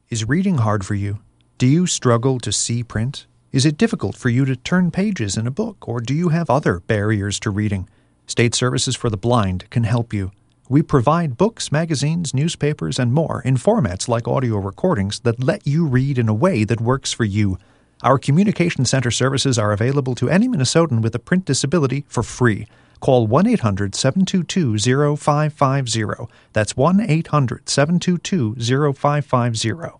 Be on the listen for a radio ad highlighting SSB’s Communication Center’s audio services for people with physical and print related disabilities.